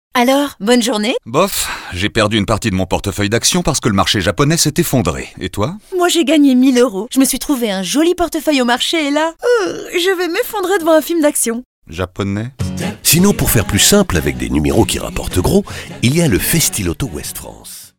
Festiloto-Ouest-France-comédie.mp3